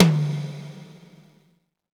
TOM XTOMH0EL.wav